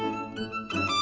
Added violin